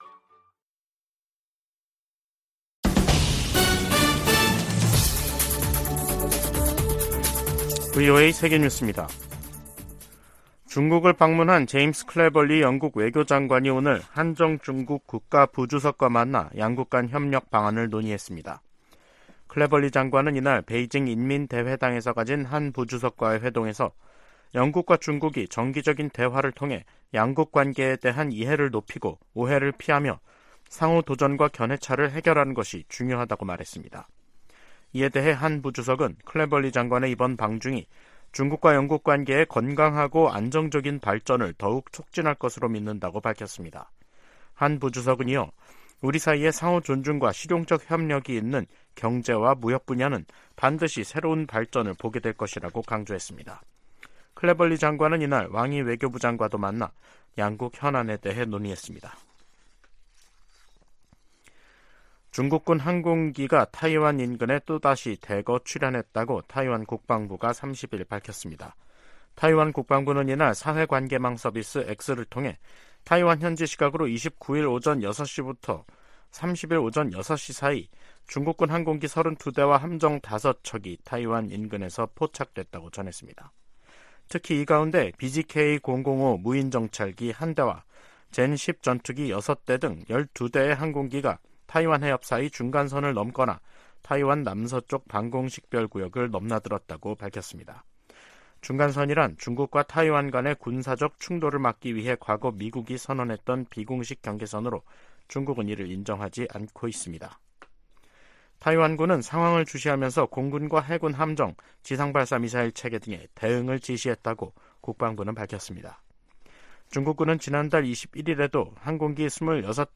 VOA 한국어 간판 뉴스 프로그램 '뉴스 투데이', 2023년 8월 30일 2부 방송입니다. 미국과 한국, 일본은 한반도 사안을 넘어 국제적으로 안보협력을 확대하고 있다고 백악관 조정관이 말했습니다. 미 국방부는 위성 발사 같은 북한의 모든 미사일 활동에 대한 경계를 늦추지 않을 것이라고 강조했습니다. 국제 핵실험 반대의 날을 맞아 여러 국제 기구들이 북한의 핵과 미사일 개발을 규탄했습니다.